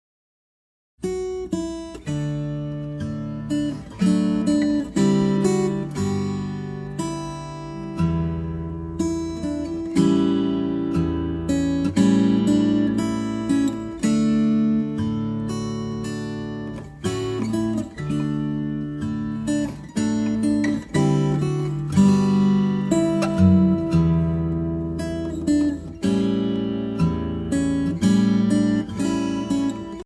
Arranger: Finger Style Guitar
Voicing: Guitar Tab